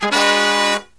Index of /90_sSampleCDs/USB Soundscan vol.29 - Killer Brass Riffs [AKAI] 1CD/Partition B/05-108SL SB1